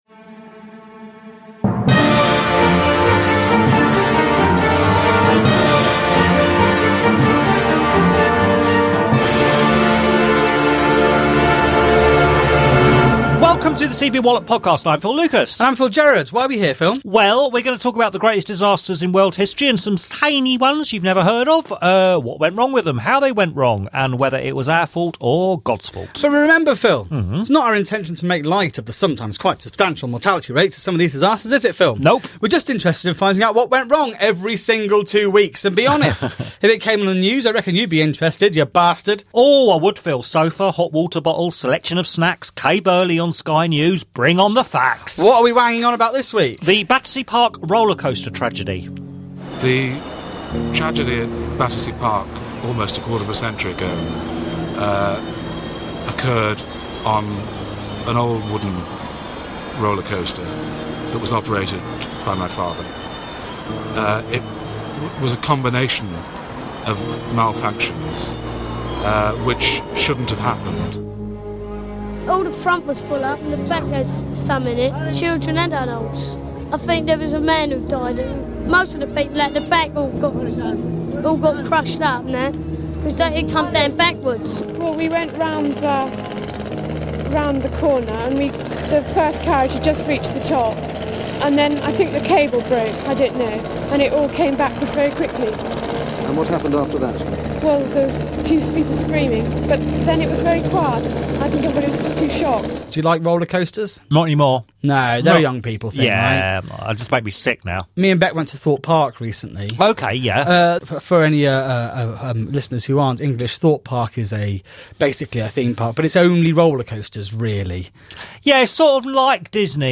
Also - as you would expect - roller coaster chat is quite prevalent. Title music